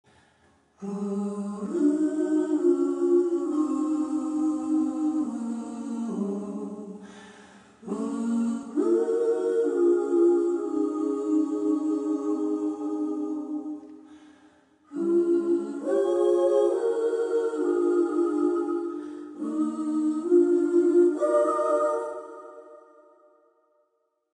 sorry for got to inlude the mp3 vietnames call " nhac be`"